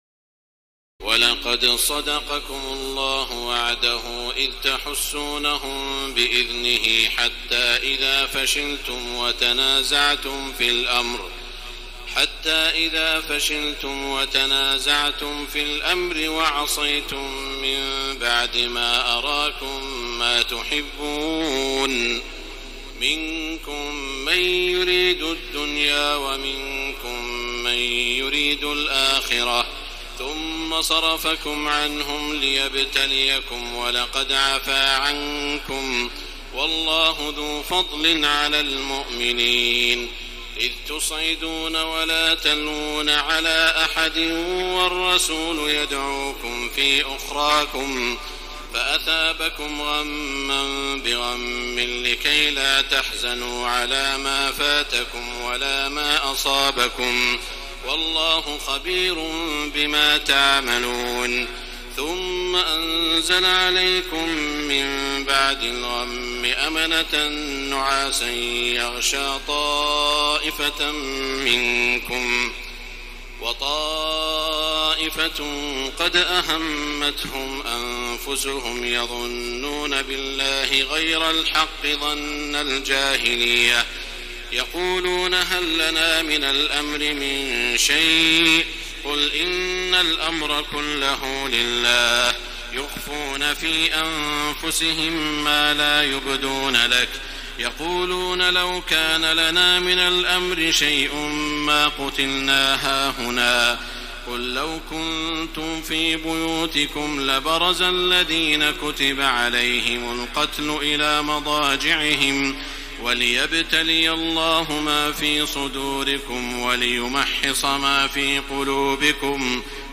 تراويح الليلة الرابعة رمضان 1433هـ من سورتي آل عمران (152-200) و النساء (1-18) Taraweeh 4 st night Ramadan 1433H from Surah Aal-i-Imraan and An-Nisaa > تراويح الحرم المكي عام 1433 🕋 > التراويح - تلاوات الحرمين